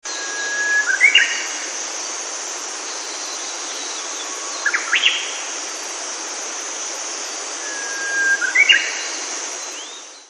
[ウグイス]
津幡町にある石川県森林公園で聞きました。森の中に響くその声は、聞く人も森の一部だと感じさせるに十分です。